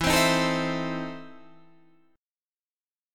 EM7sus4#5 chord